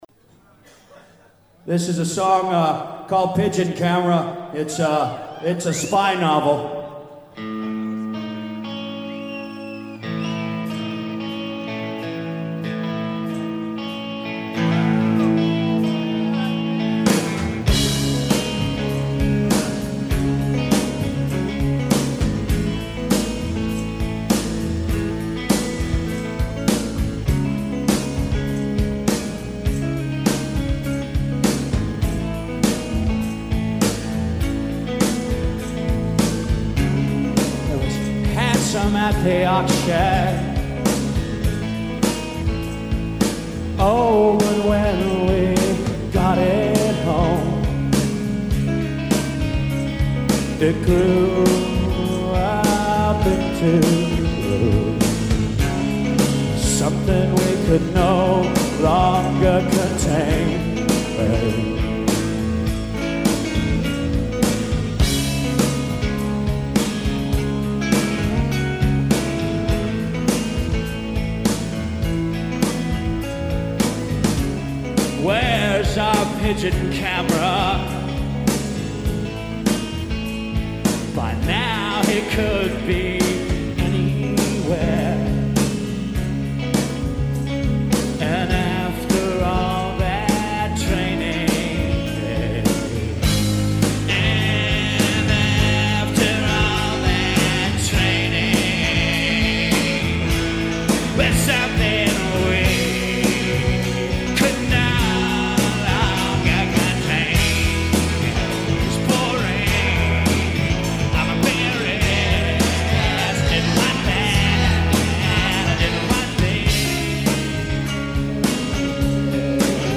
Live In 1992
Source: Radio